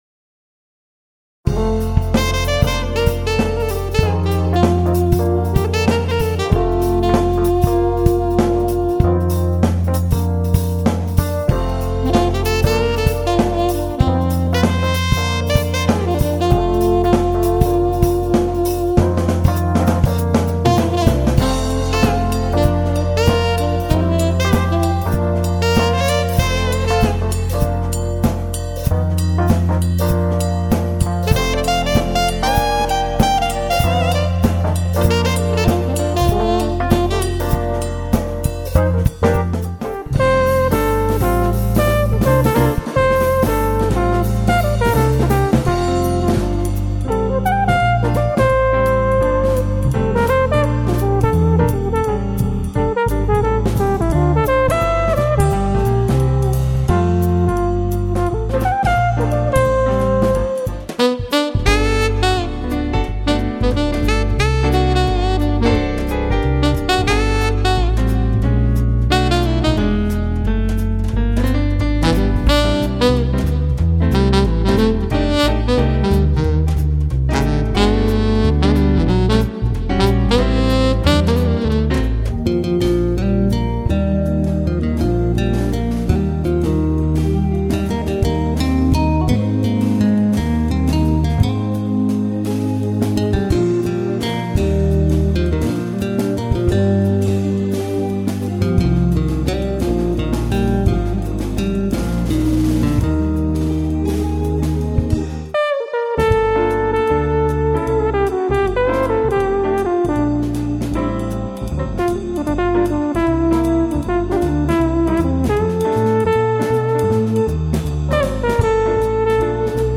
Smooth Jazz Combo
EVI (Electronic Valve Instrument) wind controller
keyboard / bass
drums